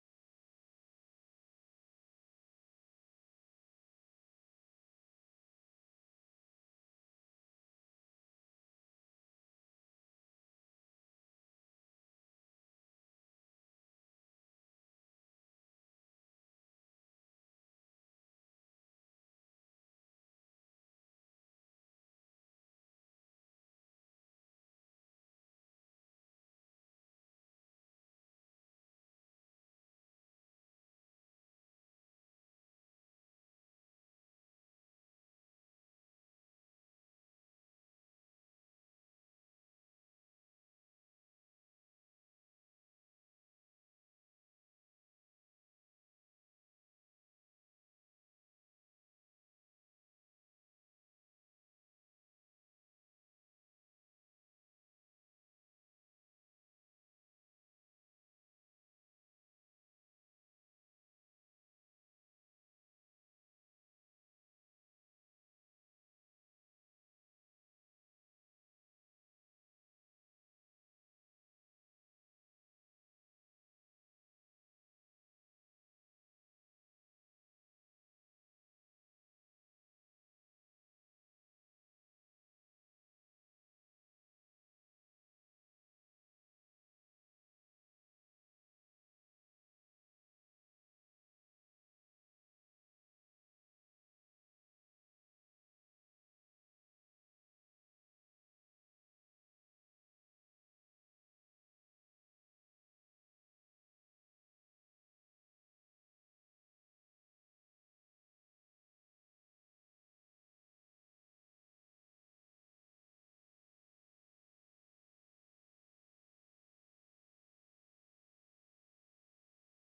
pzh_samenvatting_audiodescriptie_nieuw.mp3